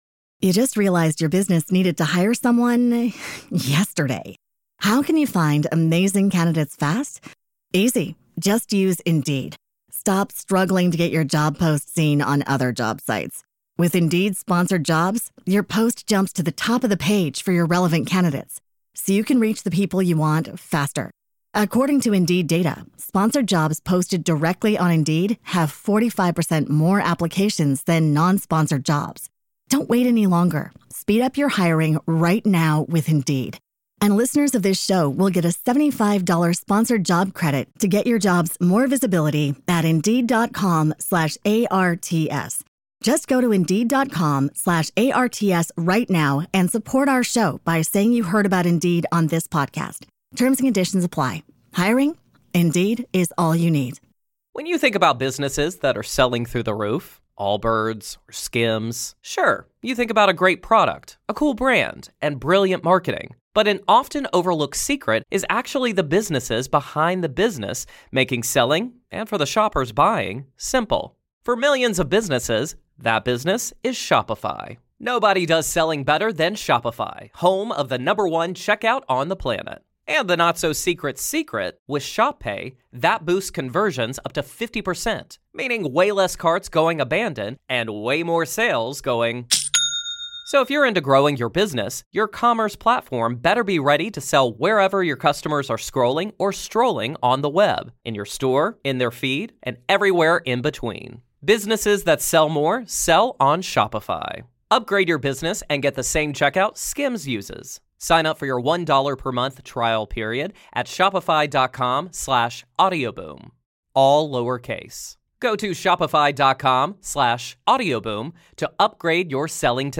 In this enlightening conversation, Dr. Kristin Neff, a pioneer in the field of self-compassion, joins us on Radically Loved to discuss the importance of being kind to oneself, the difference between self-compassion and self-esteem, and how to practice self-compassion in daily life. She shares her personal journey that led her to study self-compassion, the role of context in understanding behaviors, and the significance of community support.